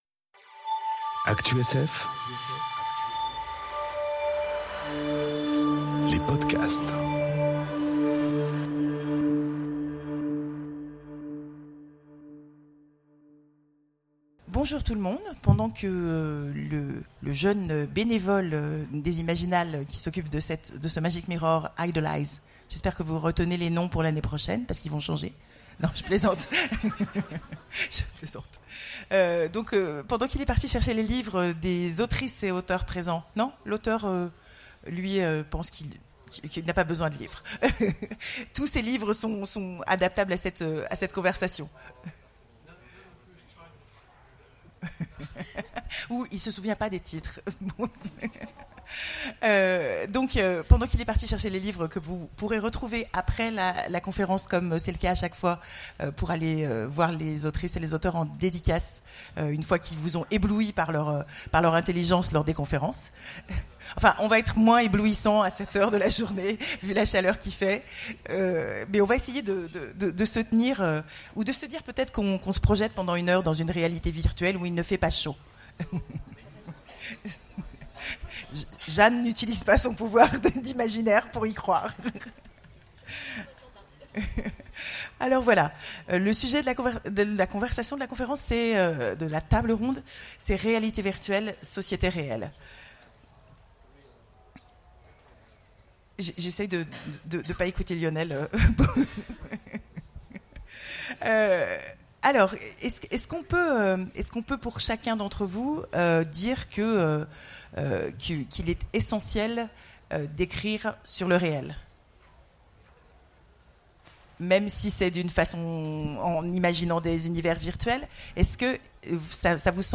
Conférence Réalités virtuelles... Sociétés réelles enregistrée aux Imaginales 2018